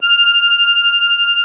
MUSETTE 1.20.wav